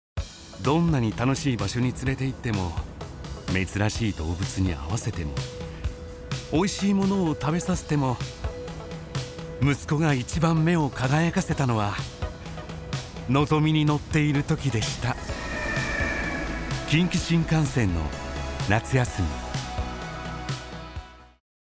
My voice is elegant and calmness, and I have extensive experience in “company introductions,” “product introductions,” and “documentaries.
– Narration –
Refreshing